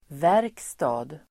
Uttal: [v'är:ksta(:d)]